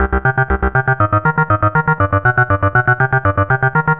Laughing Stock C 120.wav